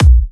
VEC3 Bassdrums Trance 14.wav